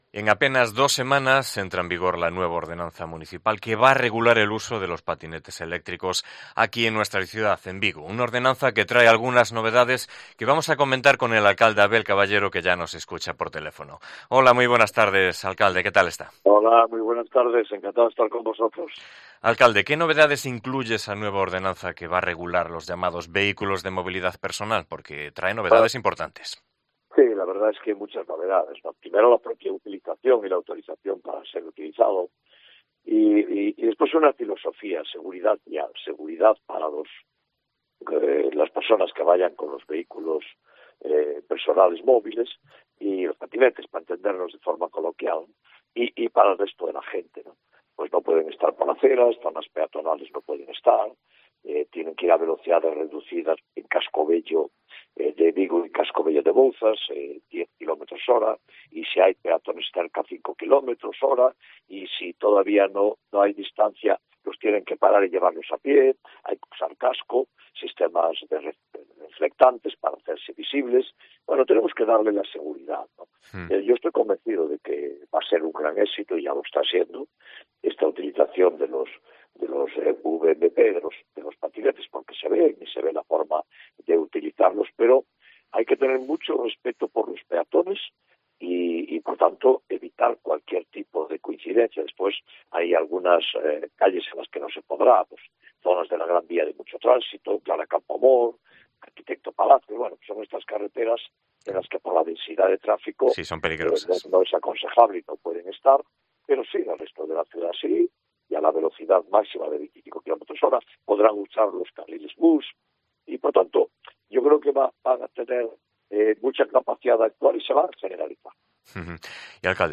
En COPE Vigo hablamos con el alcalde de Vigo para conocer la actualidad local